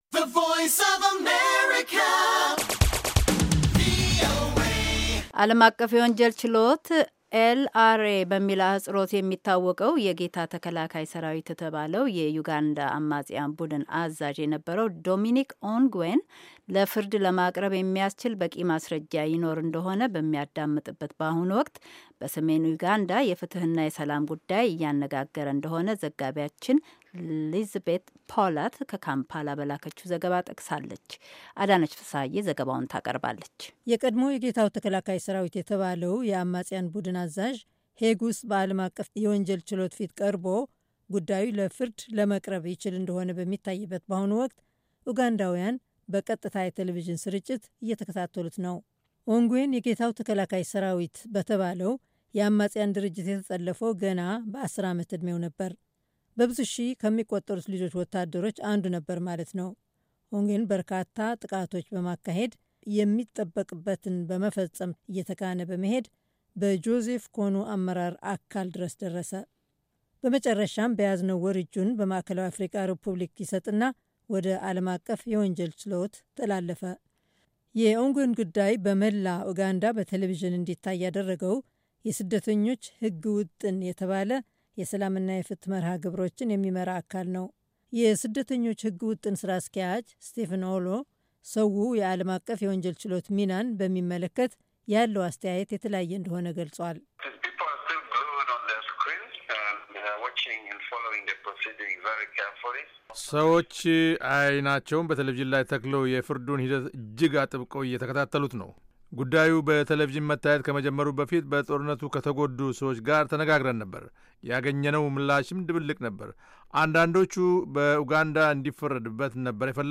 ከካምፓላ በላከችው ዘገባ ጠቅሳለች።